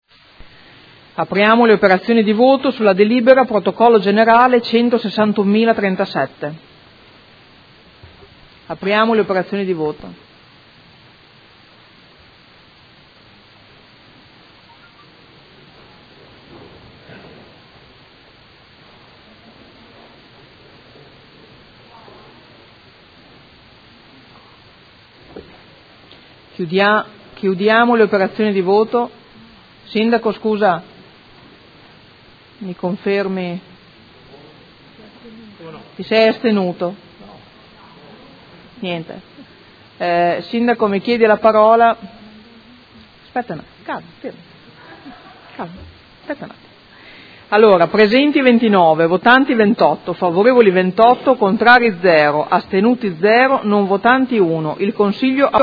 Presidente — Sito Audio Consiglio Comunale